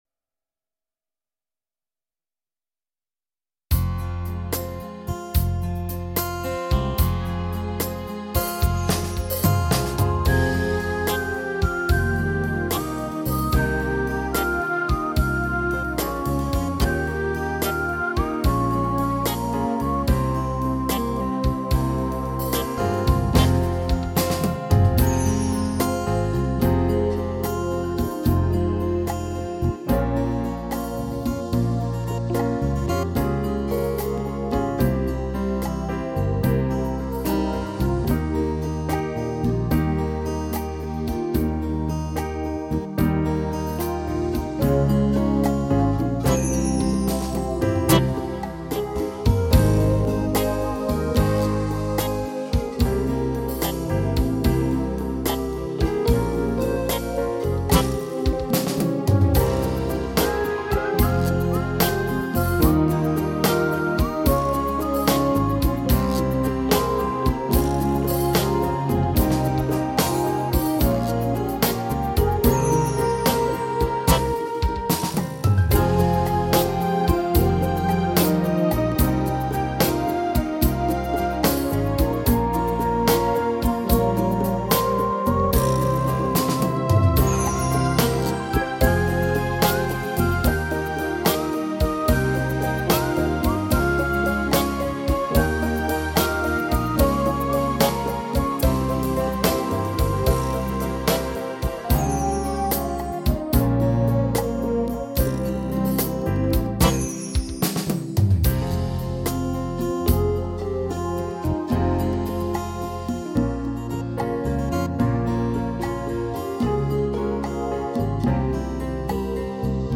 ترانيم الصلب و القيامة Added فبراير 18